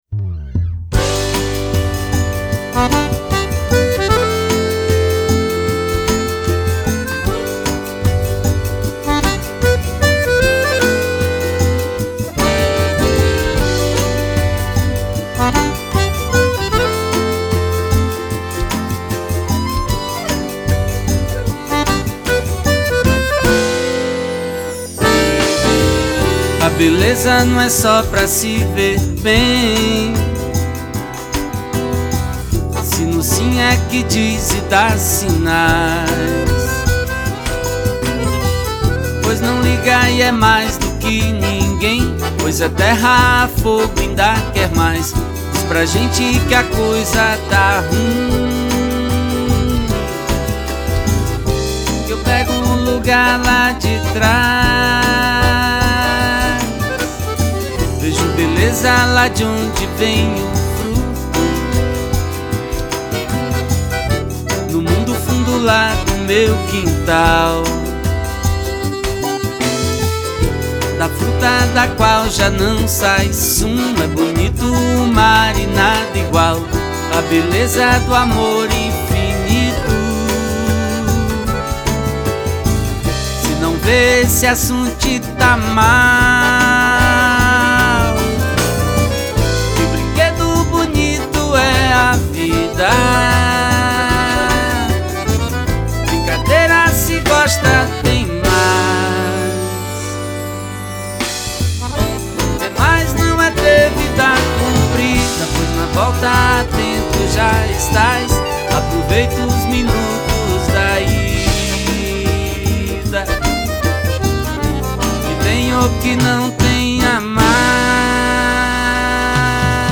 Genre : Forró